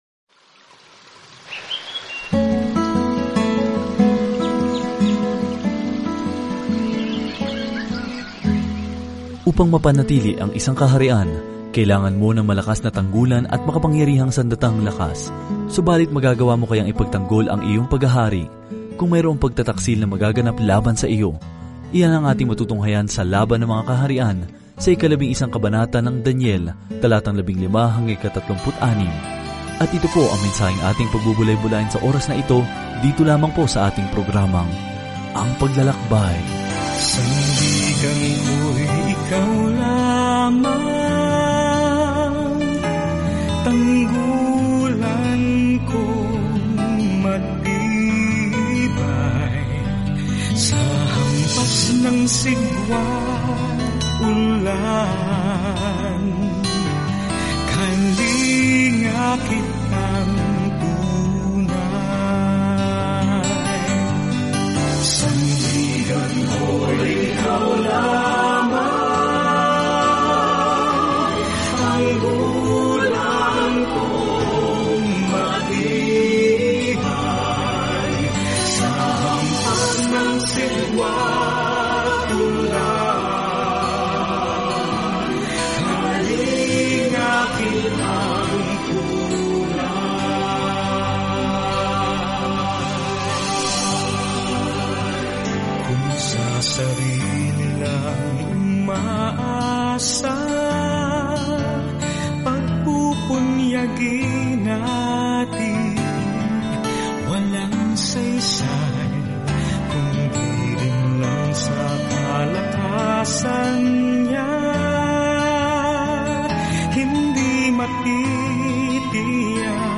Banal na Kasulatan Daniel 11:15-36 Araw 27 Umpisahan ang Gabay na Ito Araw 29 Tungkol sa Gabay na ito Ang aklat ni Daniel ay parehong talambuhay ng isang taong naniwala sa Diyos at isang makahulang pangitain kung sino ang mamamahala sa daigdig. Araw-araw na paglalakbay kay Daniel habang nakikinig ka sa audio study at nagbabasa ng mga piling talata mula sa salita ng Diyos.